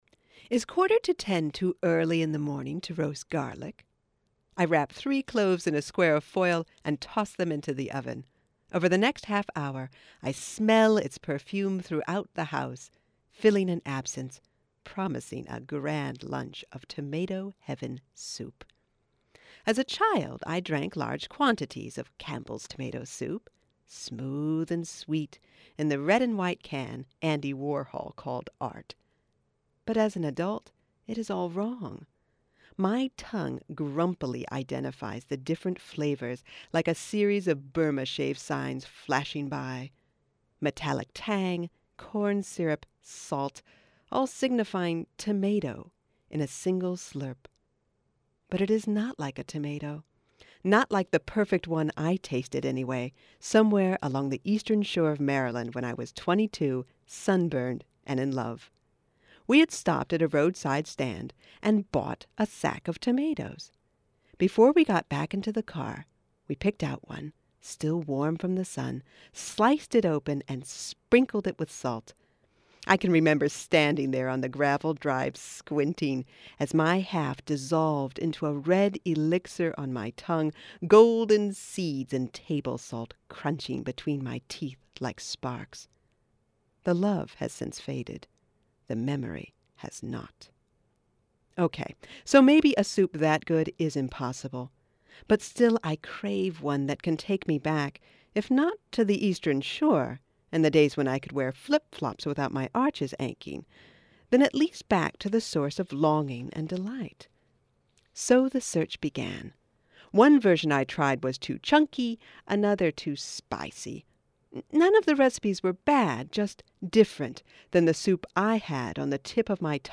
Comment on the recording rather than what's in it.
Originally aired on WMUB-FM, Oxford, Ohio (2008)